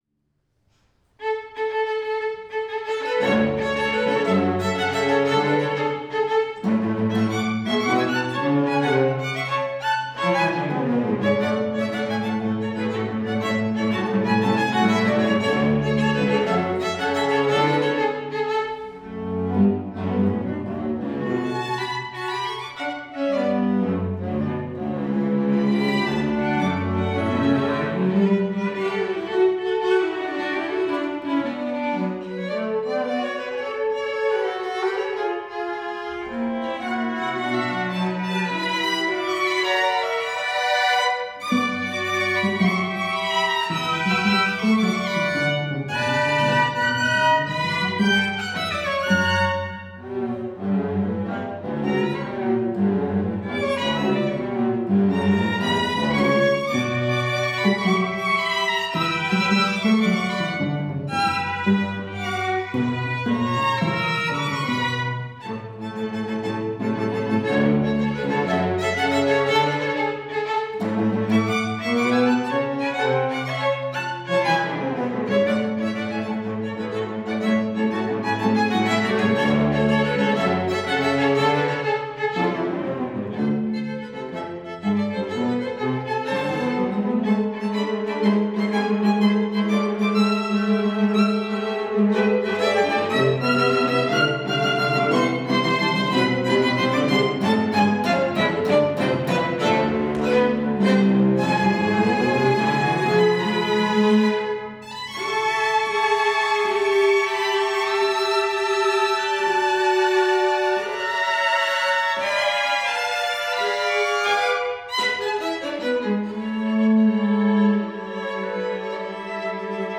For String Quartet